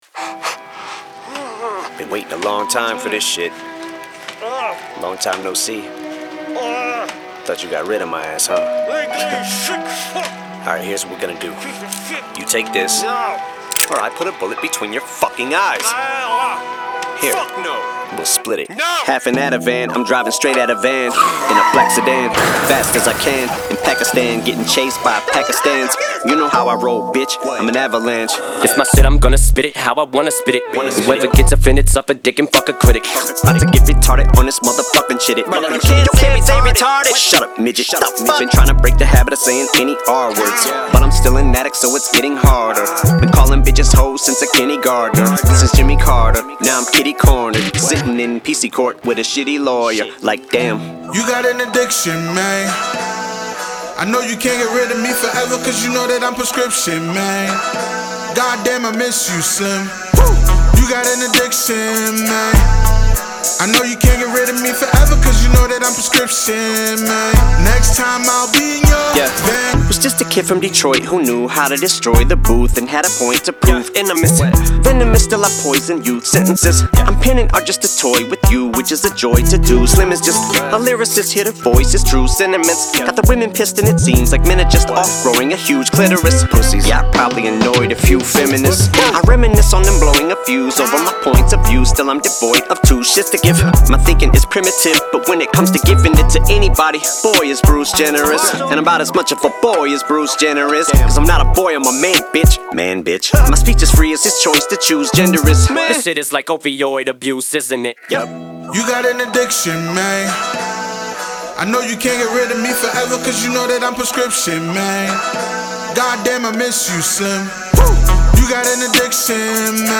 • Жанр: Hip-Hop, Rap